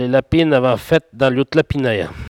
Patois